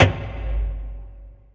Index of /kb6/Akai_MPC500/1. Kits/Garage Kit
grg amb.WAV